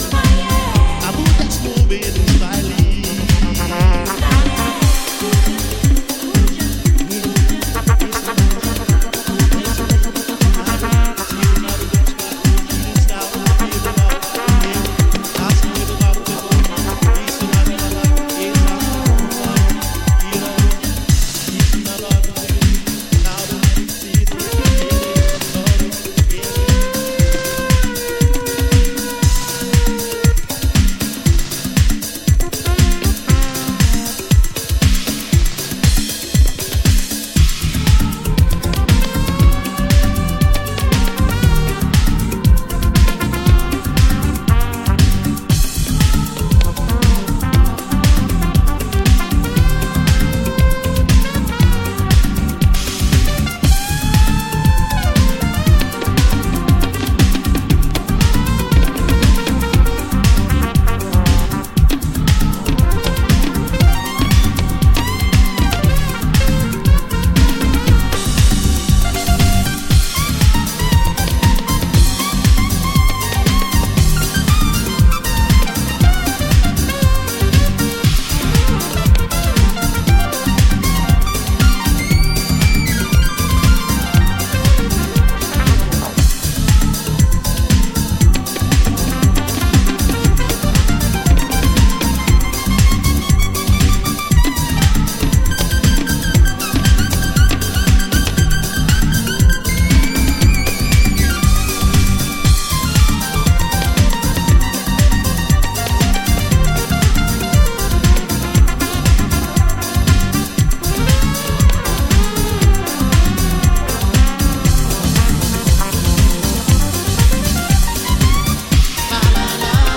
edits and overdubs of African vibrational sounds and rhythms